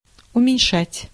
Ääntäminen
IPA: [ʊmʲɪnʲˈʂatʲ]